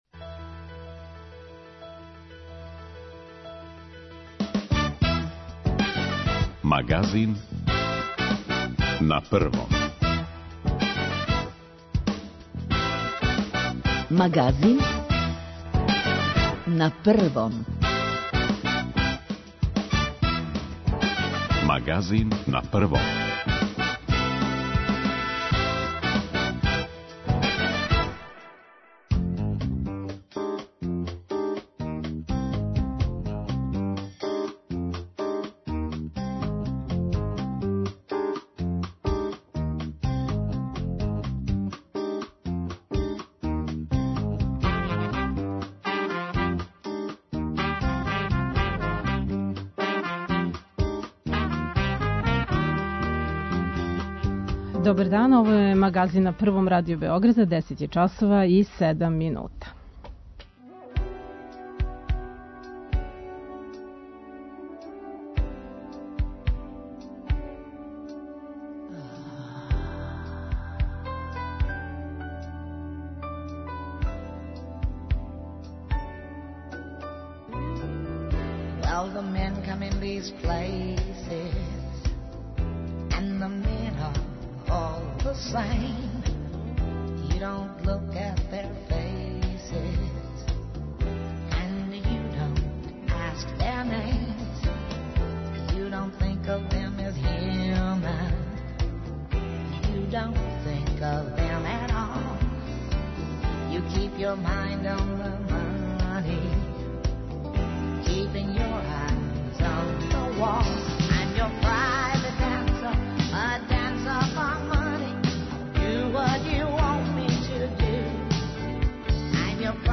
Од наших дописника сазнајемо какво је тренутно стање и колико грађани поштују прописане мере.